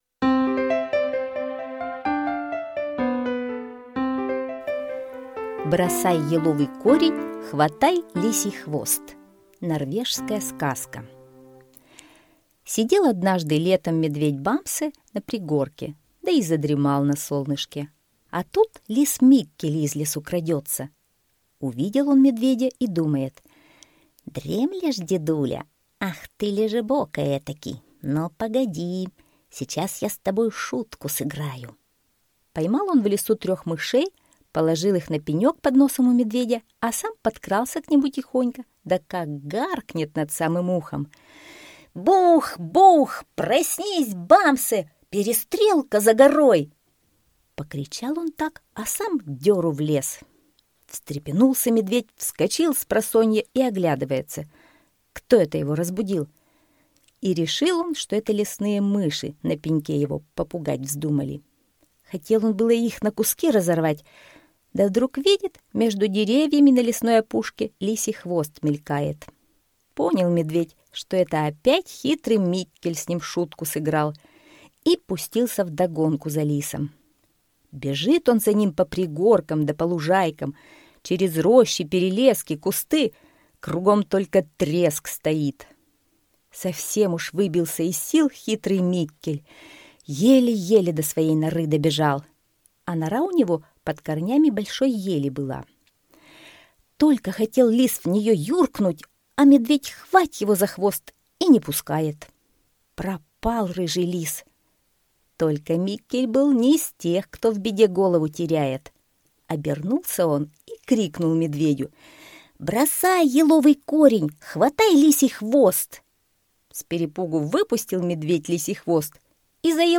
Лис Миккель и медведь Бамсе - норвежская аудиосказка - слушать онлайн